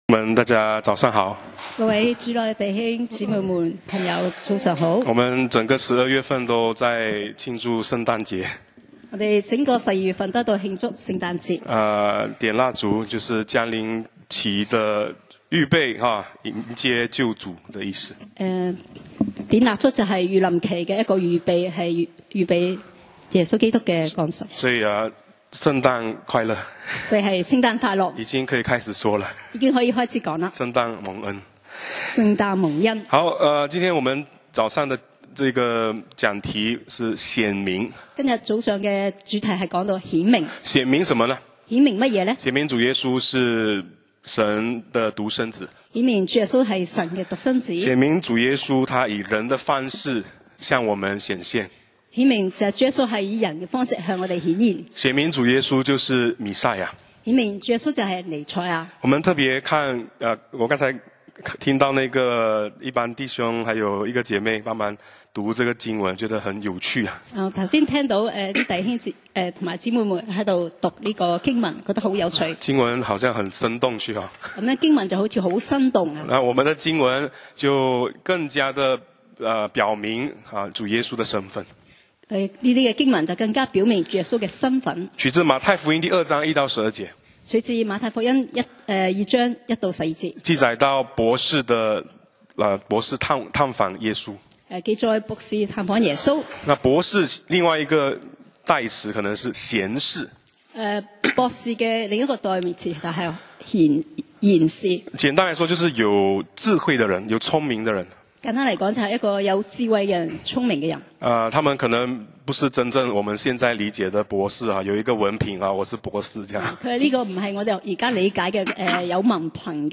講道 Sermon 題目 Topic： 显明 經文 Verses：經馬太福音 Matthew 2：1-12. 1當希律王的時候、耶穌生在猶太的伯利恆．有幾個博士從東方來到耶路撒冷、說、2那生下來作猶太人之王的在那裡。